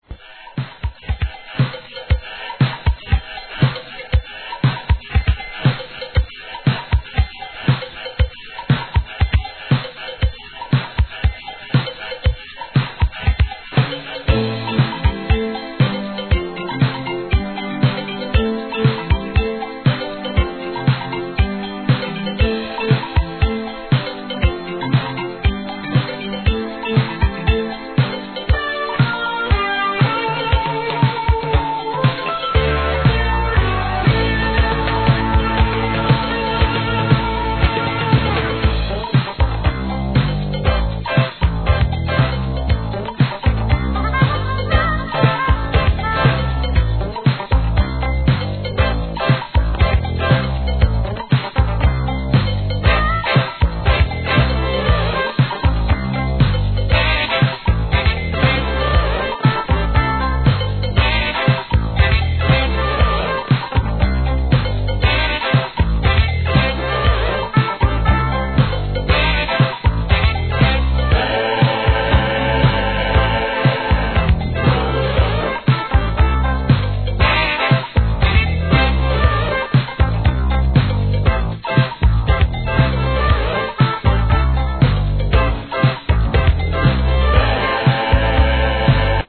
SOUL/FUNK/etc...
爽快なイントロからウキウキなトロピカル・ディスコ!!